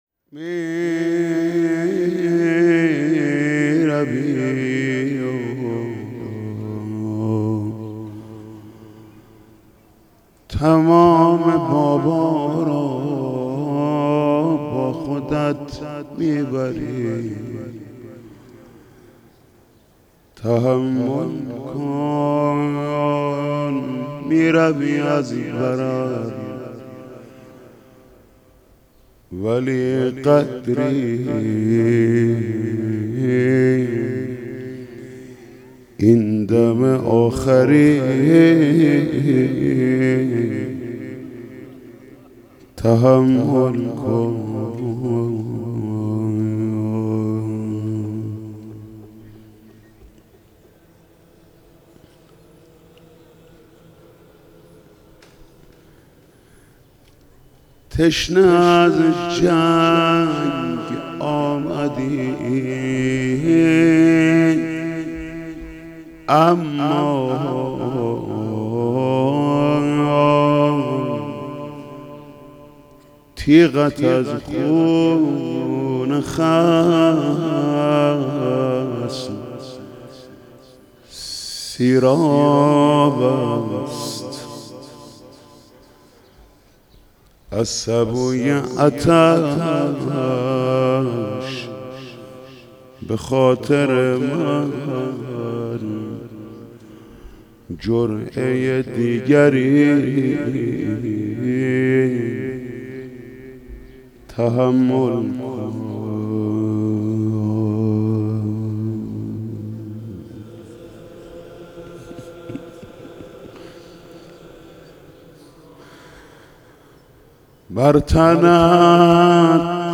مراسم شب هشتم ماه محرم در هیئت رایة العباس با مداحی محمود کریمی برگزار شد.
در ادامه، صوت و فیلم مداحی و سینه‌زنی این مراسم را مشاهده می‌کنید.